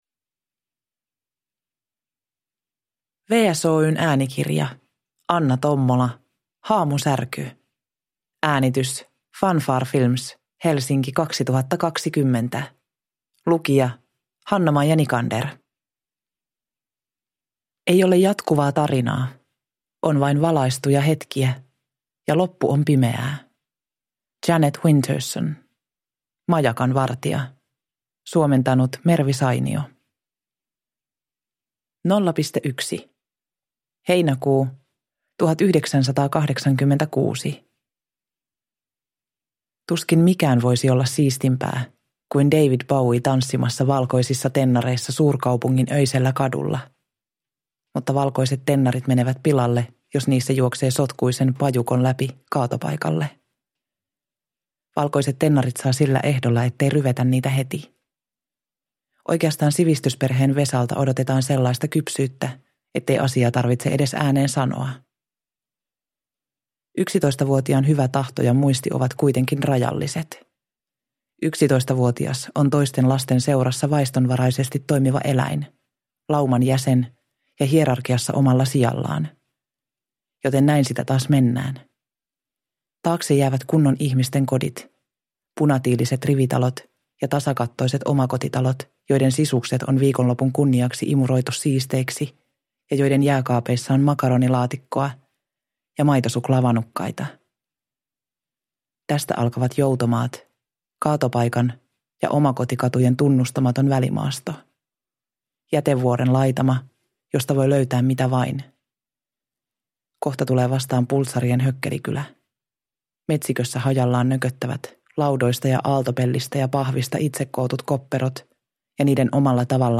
Haamusärky – Ljudbok – Laddas ner